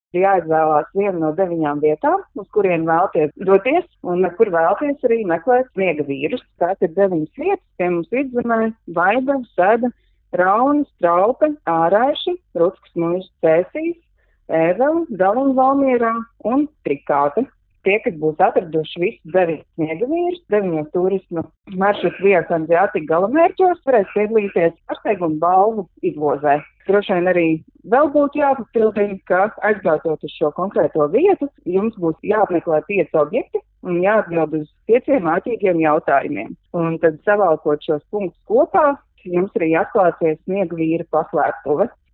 RADIO SKONTO Ziņās par ceļošanas spēli “Sniegavīru paslēpes Vidzemē”